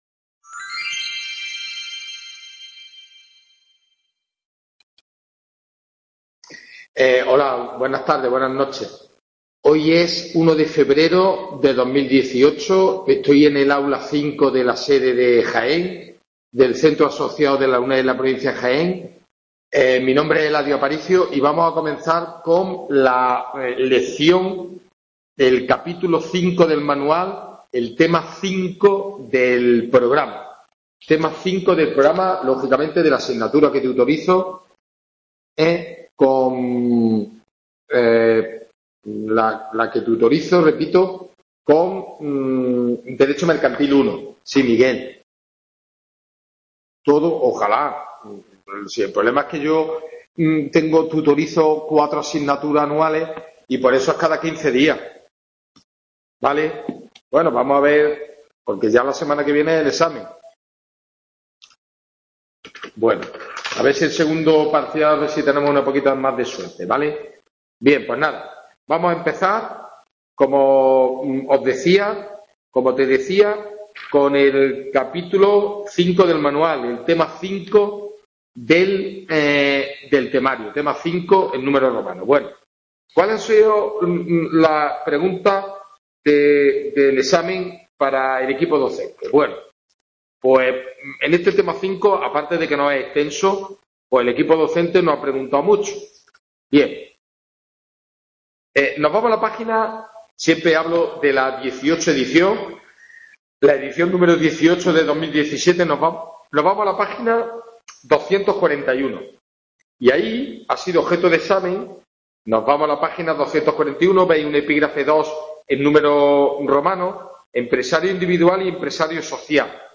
WEB CONFERENCIA "Dº MERCANTIL I